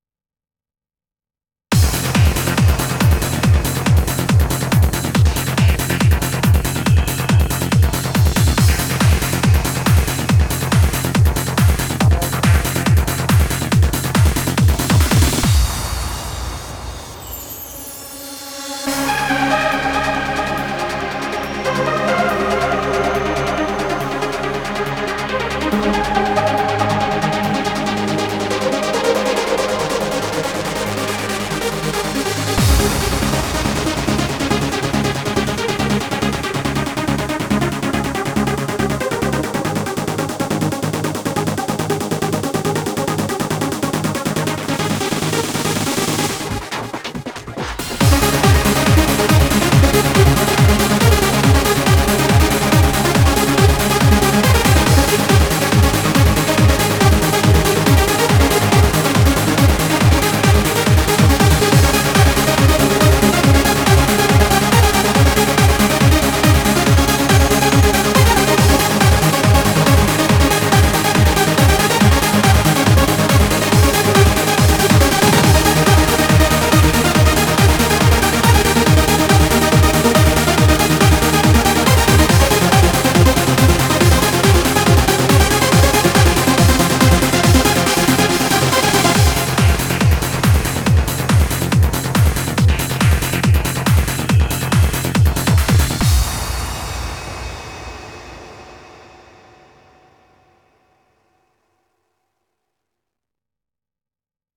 BPM140
Comments[TRANCE]